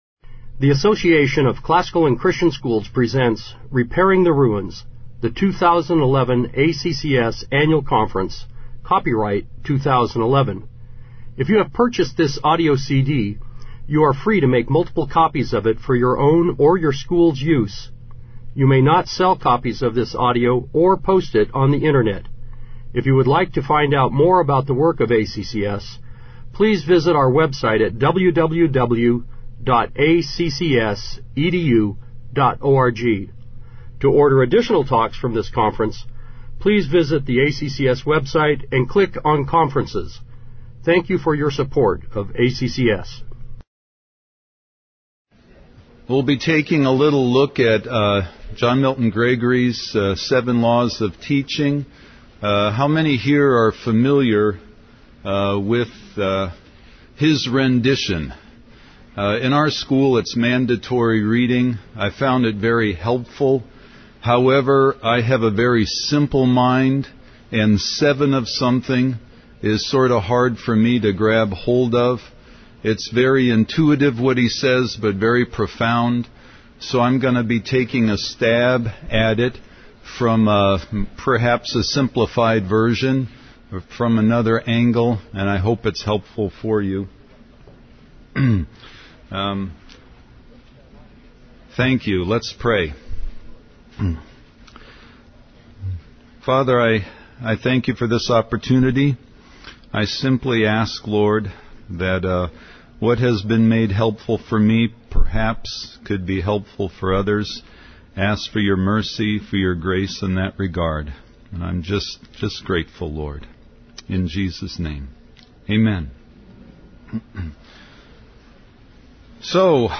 2011 Workshop Talk | 0:51:55 | All Grade Levels, General Classroom
Speaker Additional Materials The Association of Classical & Christian Schools presents Repairing the Ruins, the ACCS annual conference, copyright ACCS.